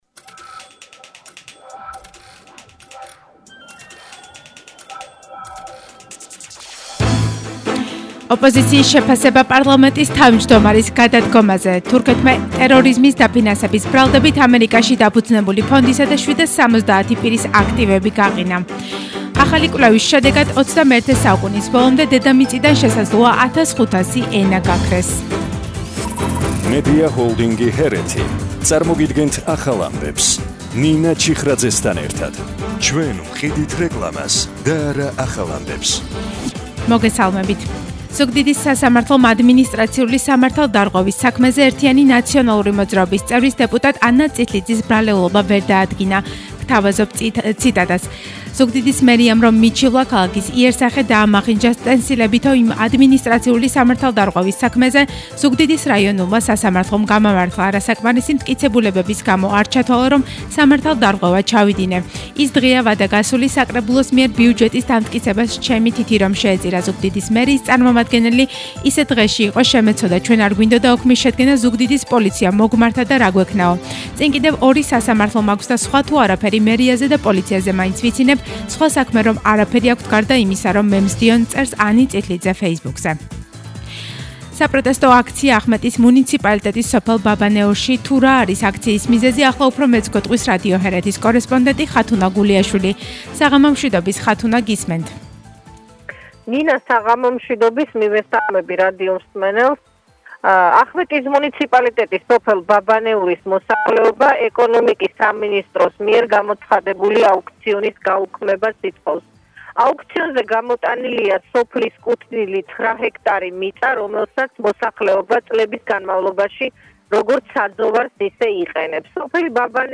ახალი ამბები 20:00 საათზე – 24/12/21